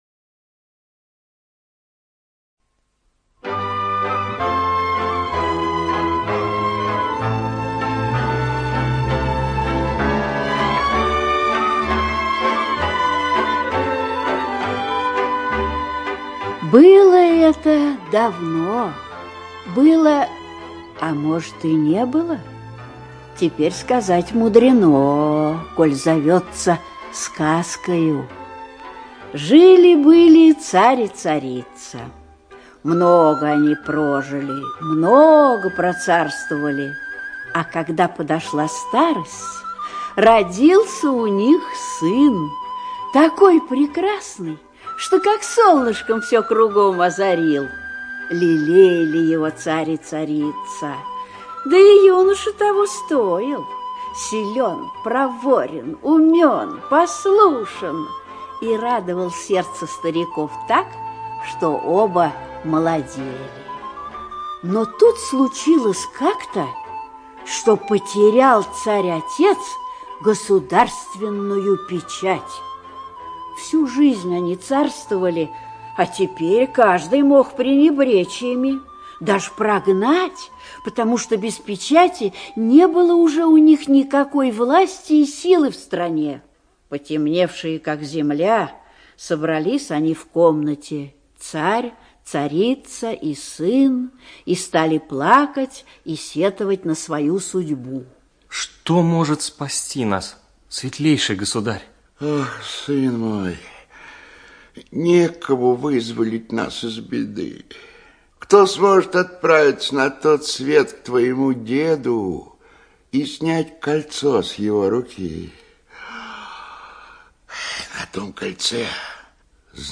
ЖанрСказки, Детский радиоспектакль